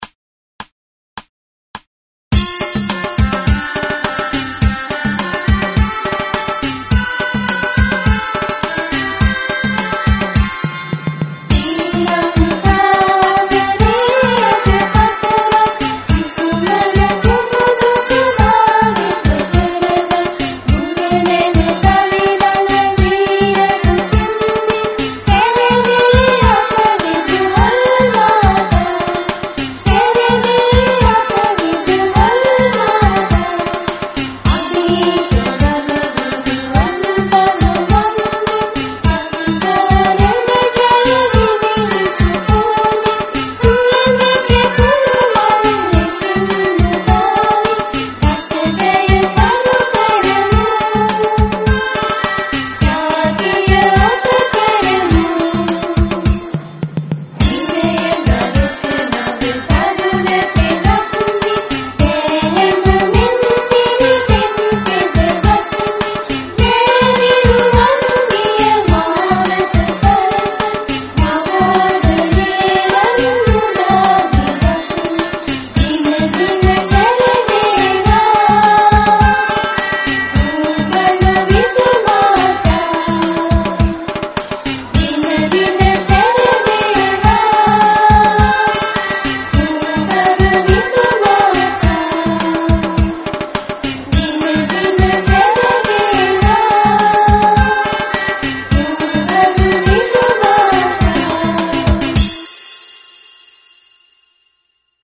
පාසල් ගීතය
Poogalla school anthem.mp3